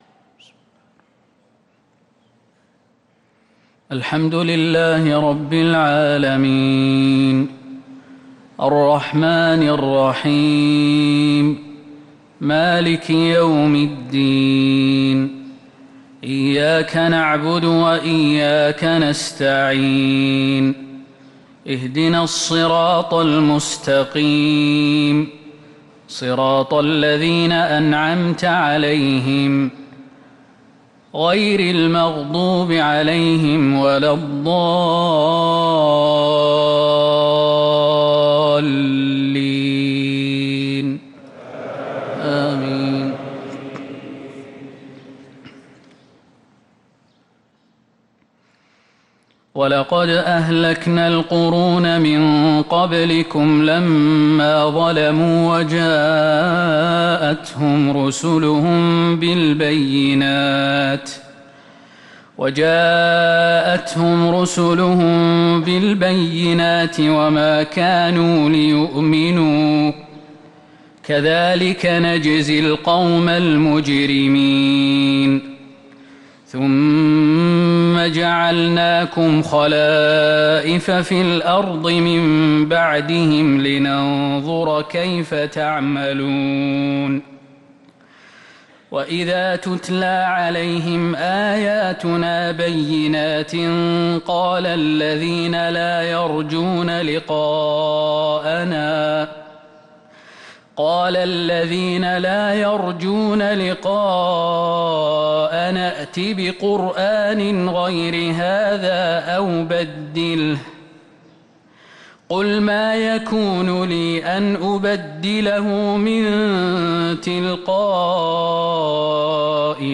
صلاة الفجر للقارئ خالد المهنا 28 محرم 1445 هـ
تِلَاوَات الْحَرَمَيْن .